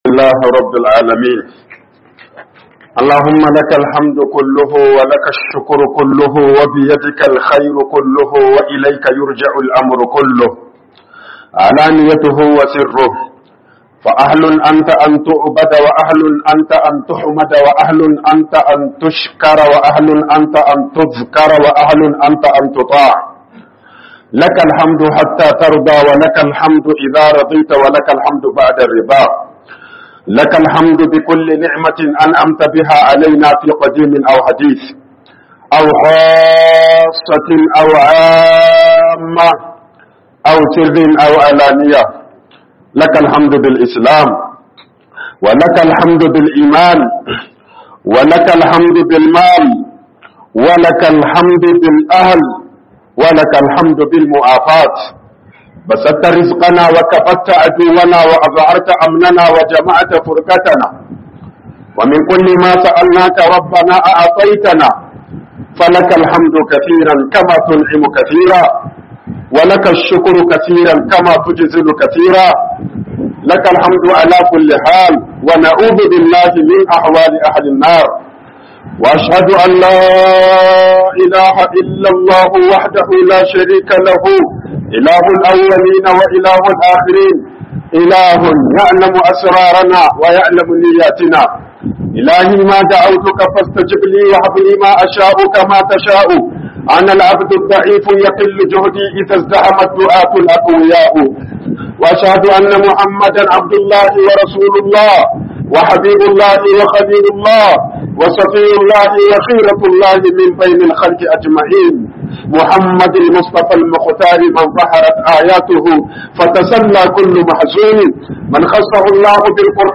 DABI'UN MUTANE - HUƊUBOBIN JUMA'A